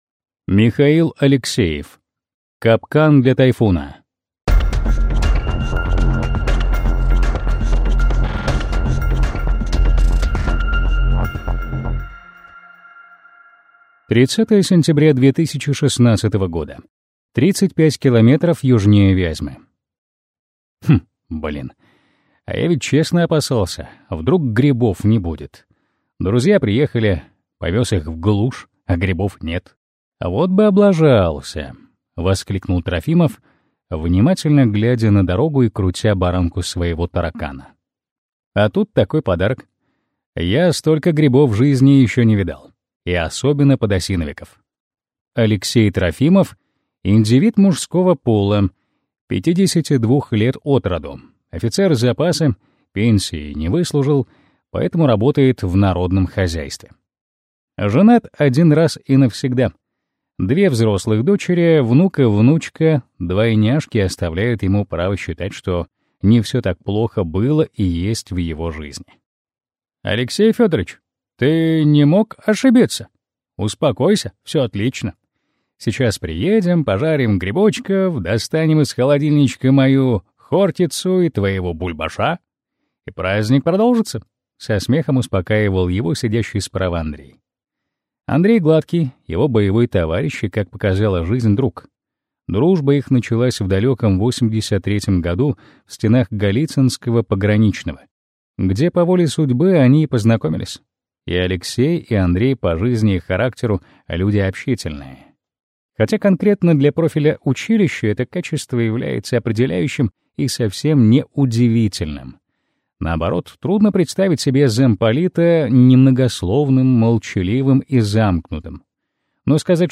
Аудиокнига Капкан для «Тайфуна» | Библиотека аудиокниг